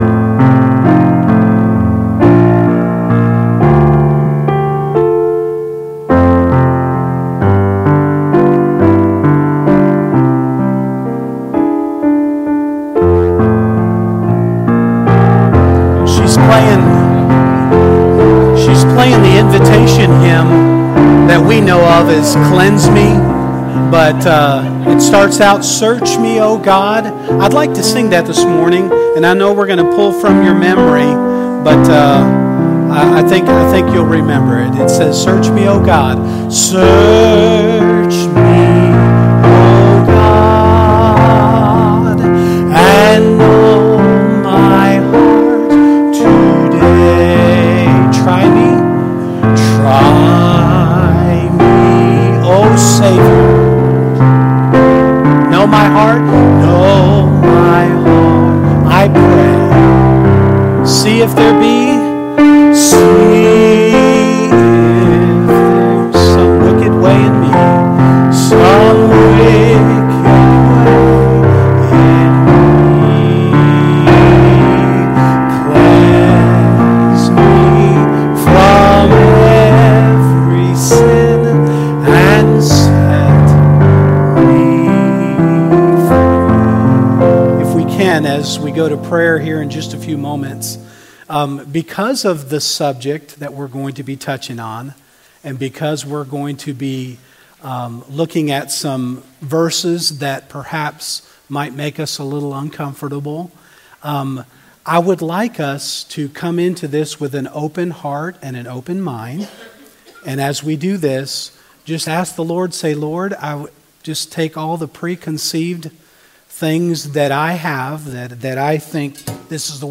12-03-23 Sunday School Lesson | Buffalo Ridge Baptist Church